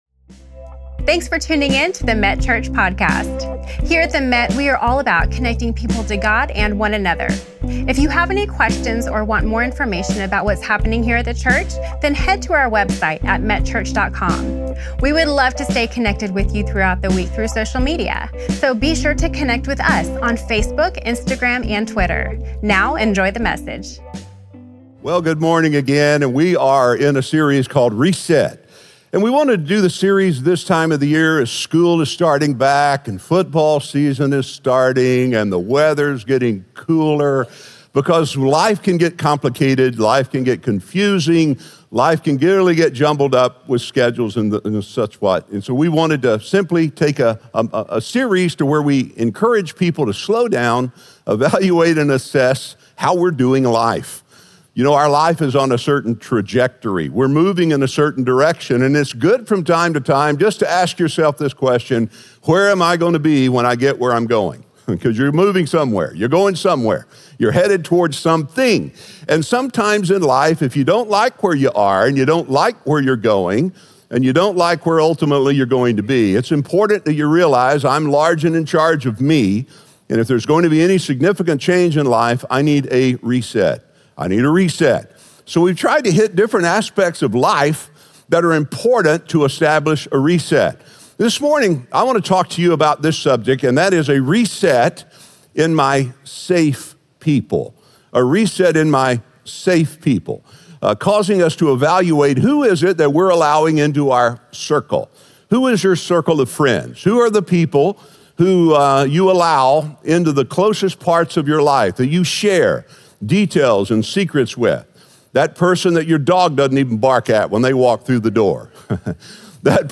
Senior Pastor